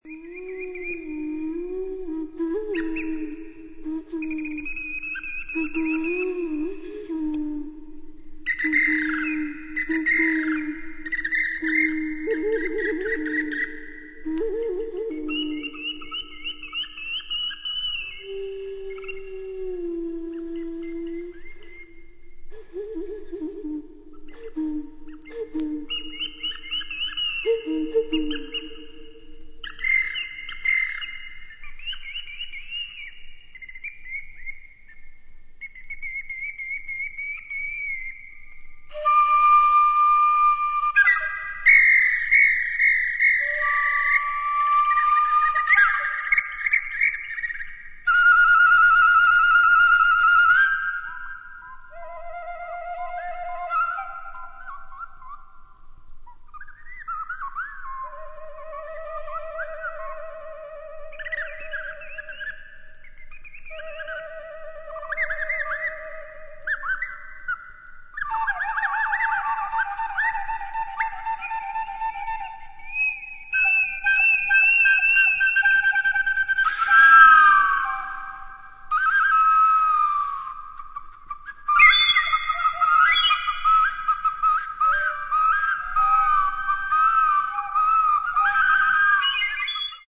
サクソフォーン、インド竹笛、ボリビア笛、鳥笛、オカリナ etc.
ギター
ベース